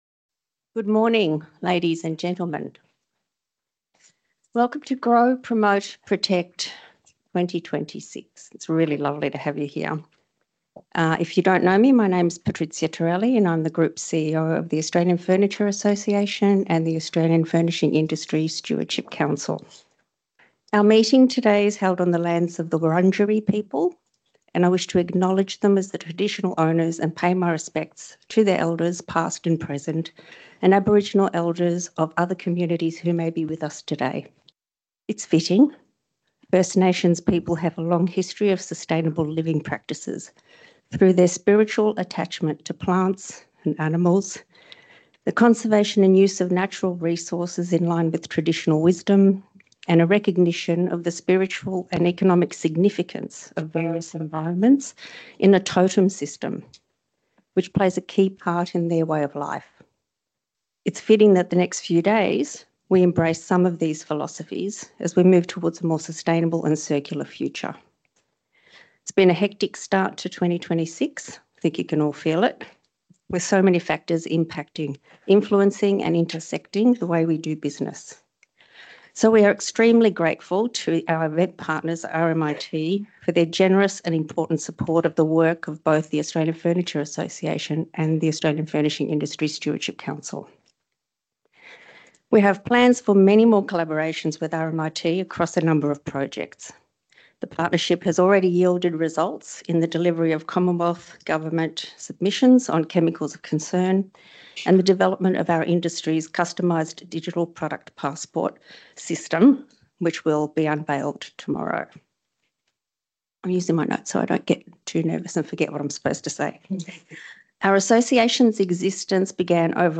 The below audio is of the opening remarks from day one